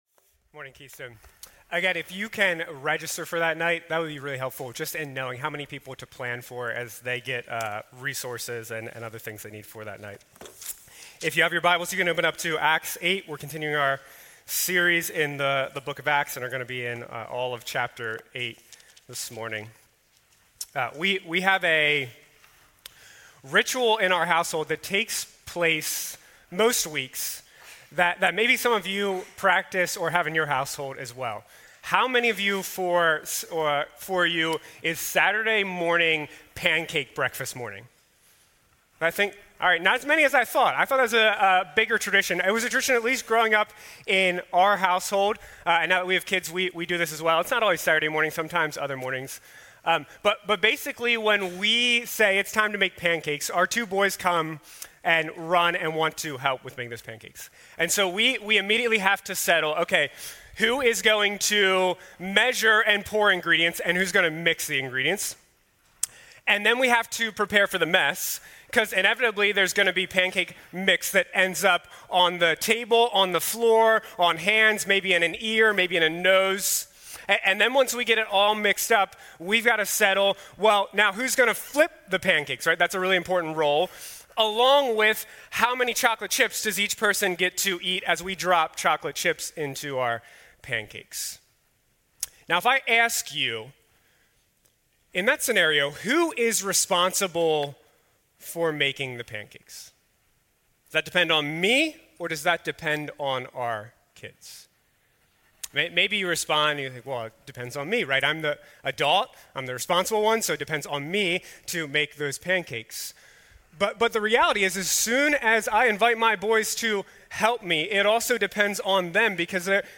These are sermons delivered during the Sunday morning worship services of Keystone Church, an Evangelical Free Church in Paradise, PA, USA.